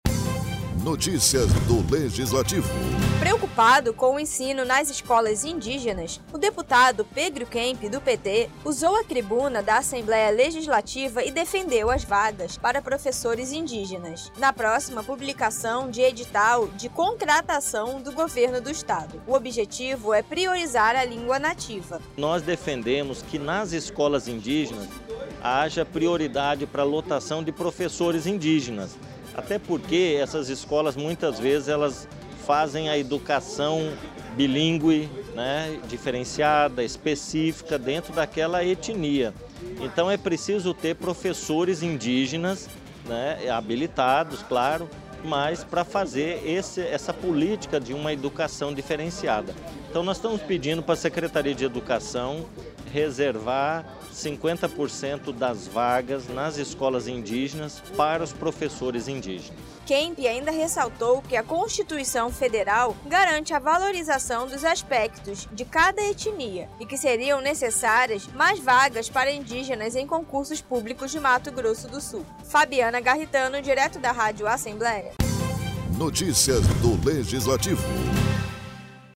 O deputado Pedro Kemp, do PT usou a tribuna para defender as vagas para professores indígenas, na próxima publicação de edital de contratação pelo Governo do Estado.